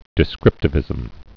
(dĭ-skrĭptə-vĭzəm)